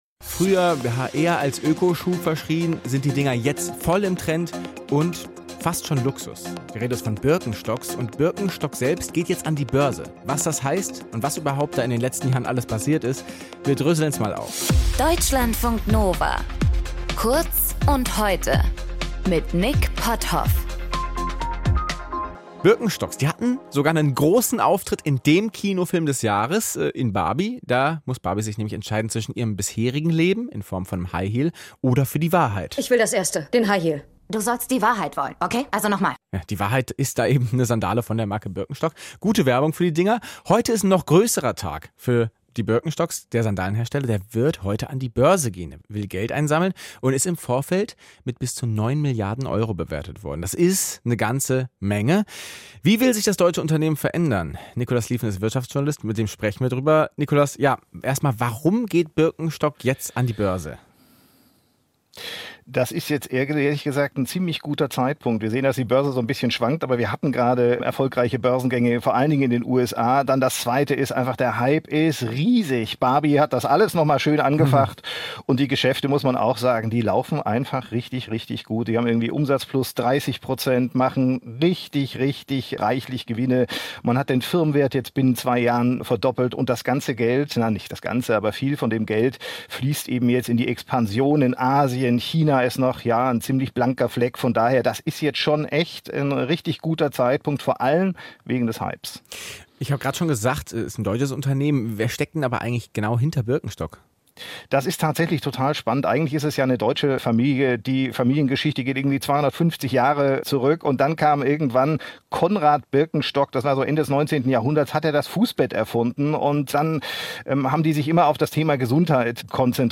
Kommentar: Familienunternehmen sind schlechter als ihr Ruf